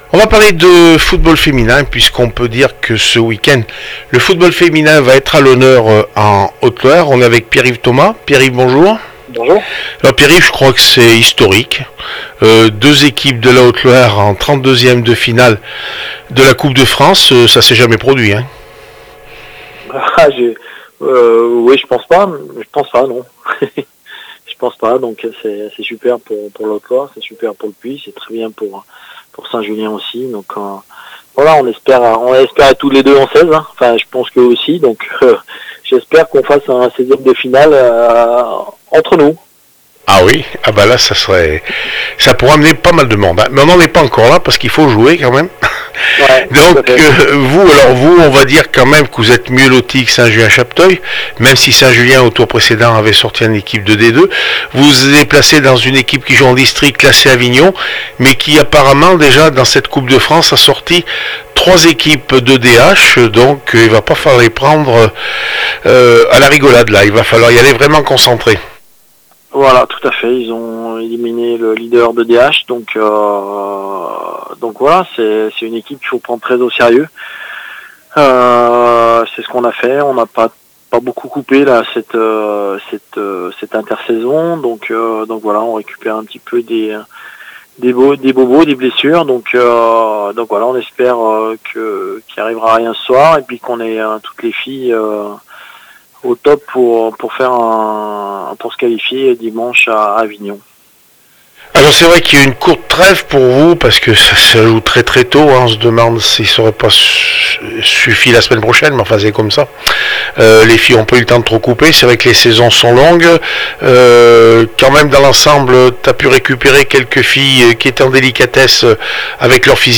AVANT MATCH